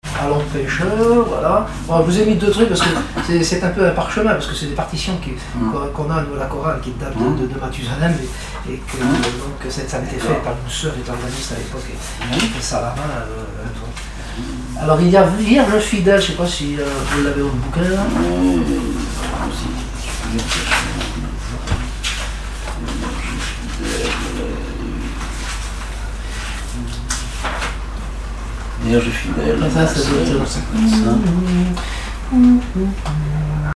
Mémoires et Patrimoines vivants - RaddO est une base de données d'archives iconographiques et sonores.
Cantiques de la chorale